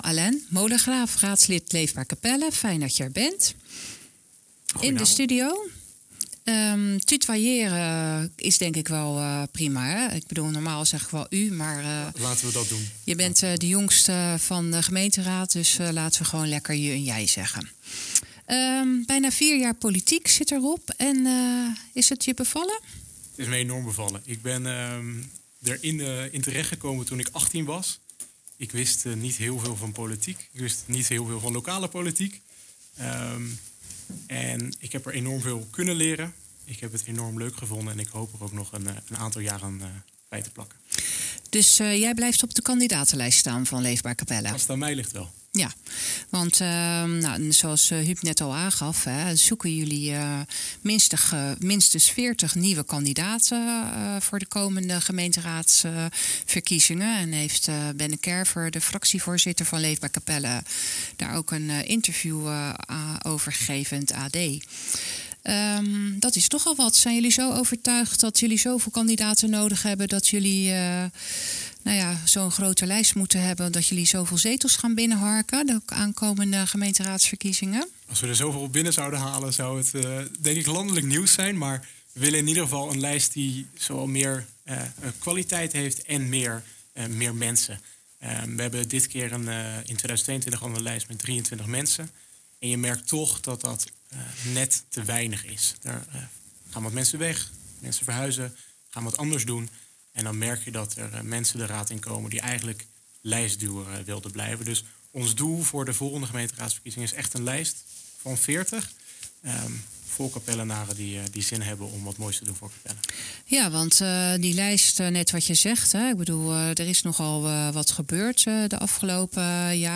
in gesprek met Leefbaar Capelle raadslid Alain Molengraaf die ook vertelt wat voor hem het "Leefbaar gevoel' is.